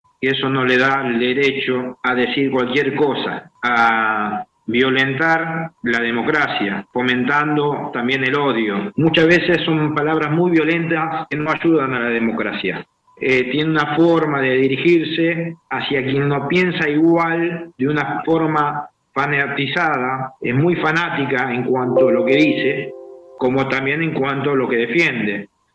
El edil radical Alberto Trisciuzzi dijo en la última sesión ordinaria que la presidenta de Abuelas de Plaza de Mayo, Estela de Carlotto, “es muy fanática en lo que dice y en lo que defiende” y que “no tiene derecho a violentar a la democracia fomentando el odio”.